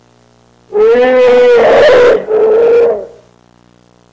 Elephant (howl) Sound Effect
Download a high-quality elephant (howl) sound effect.
elephant-howl.mp3